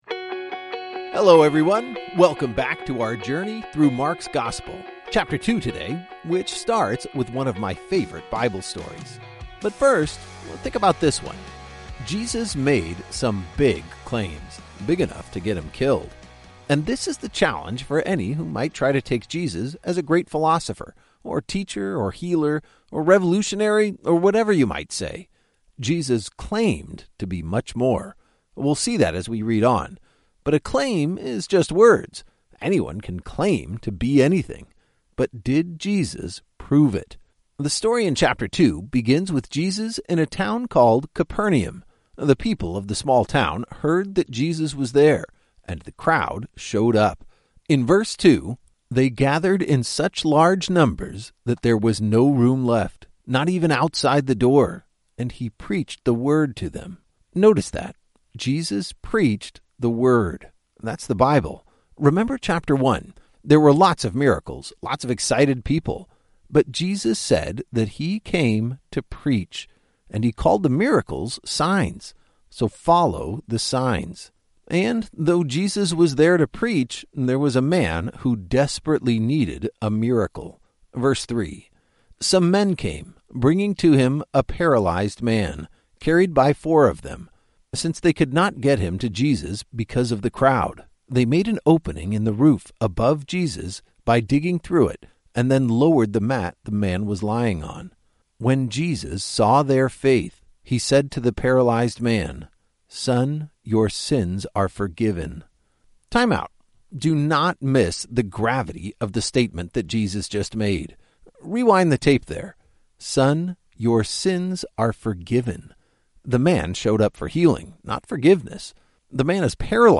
Every chapter brings new insights and understanding as your favorite teachers explain the text and bring the stories to life.